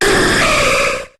Cri de Ho-Oh dans Pokémon HOME.